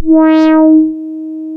MG MOD.D#4 1.wav